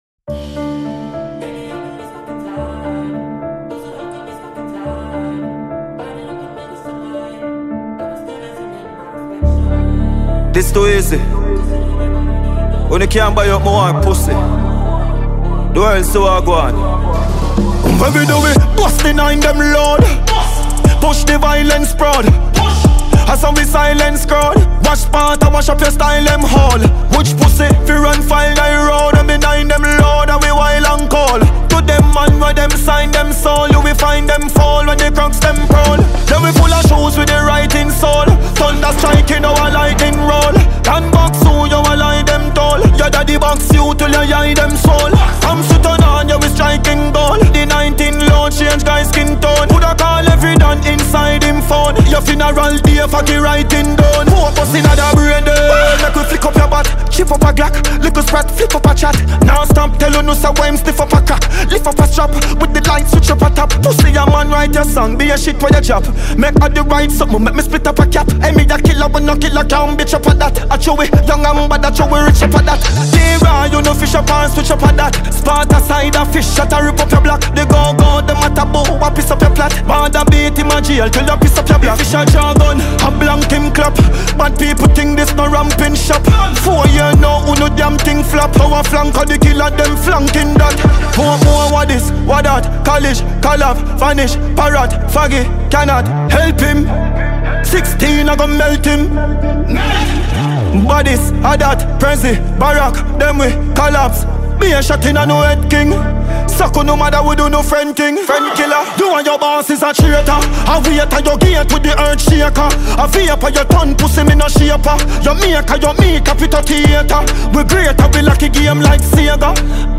Dancehall Music
is dark, aggressive, and packed with vivid imagery.